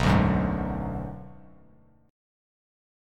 Bm6 Chord
Listen to Bm6 strummed